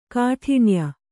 ♪ kāṭhiṇya